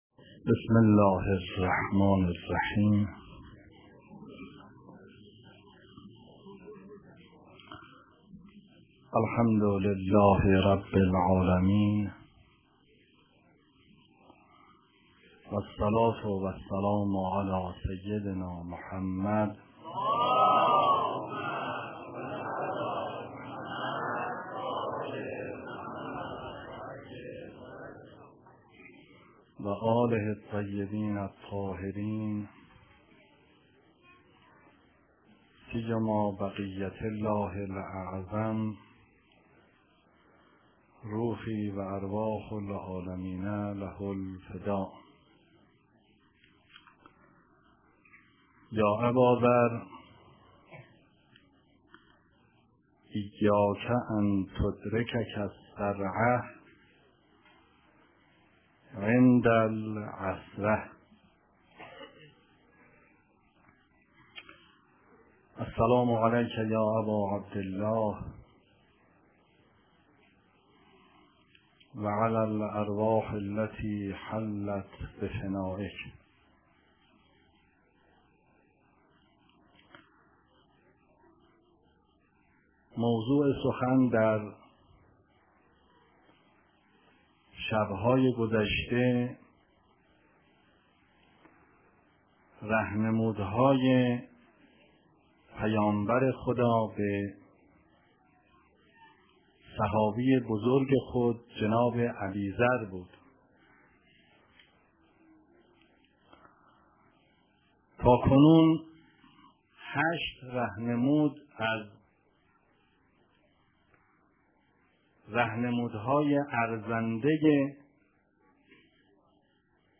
سخنرانی در آستان مقدس حضرت عبدالعظیم حسنی علیه السلام در تاریخ نهم محرم 1389